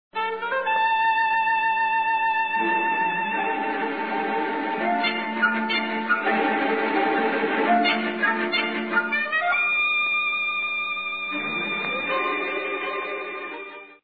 almost always accompanied by a four note
fanfare which is followed by